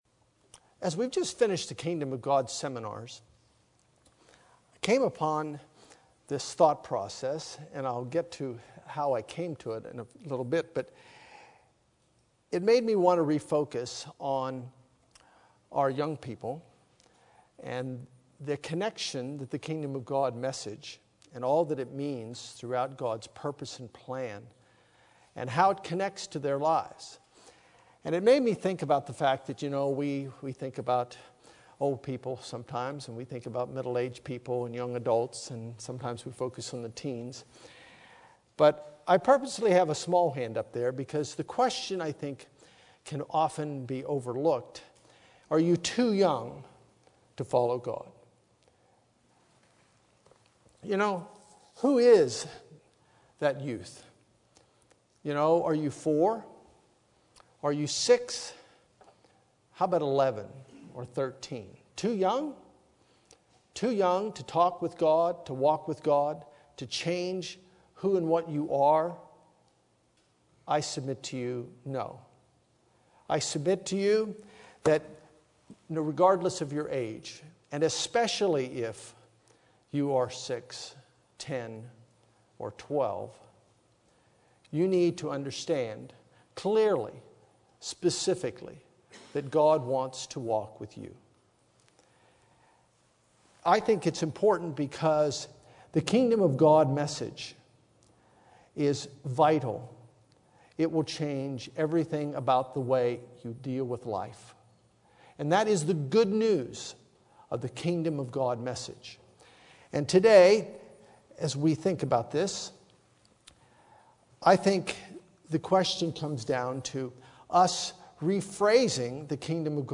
Given in Los Angeles, CA
UCG Sermon Studying the bible?